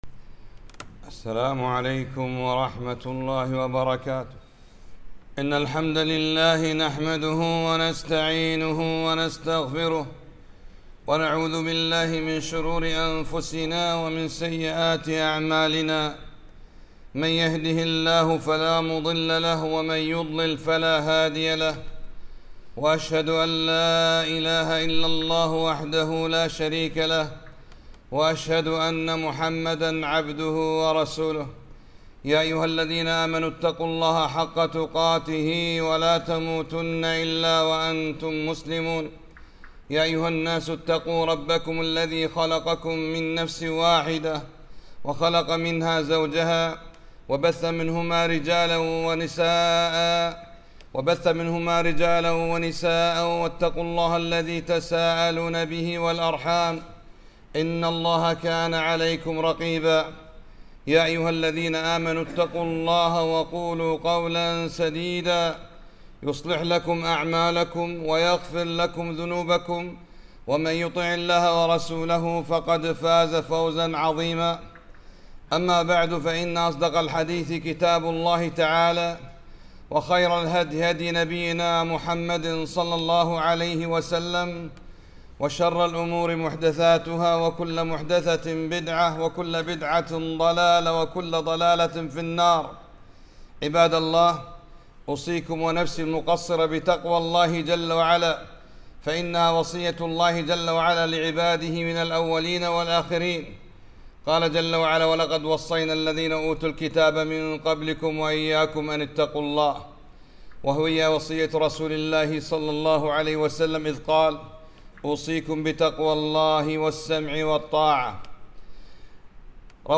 خطبة - لولا البلاء لقدمنا على الله عز وجل مفاليس